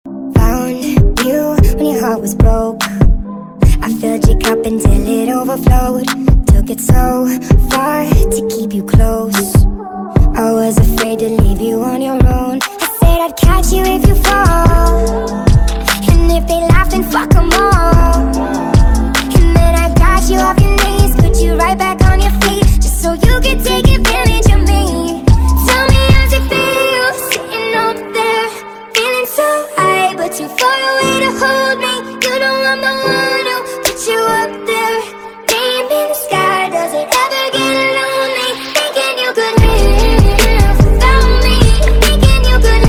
with a faster, more intense tempo